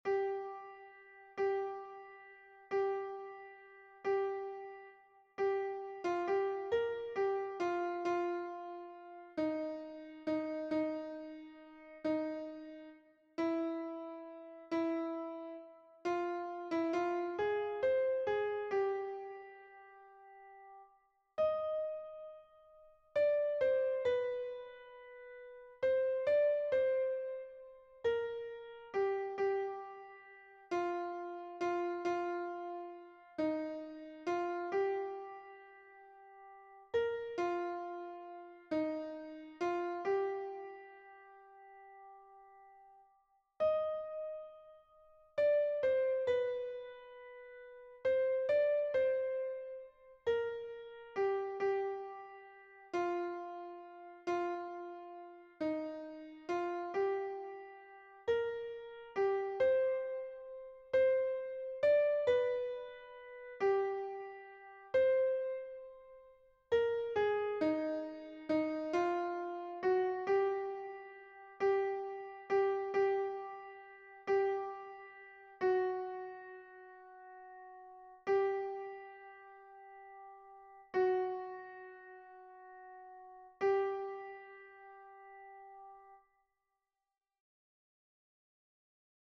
Version piano
Soprano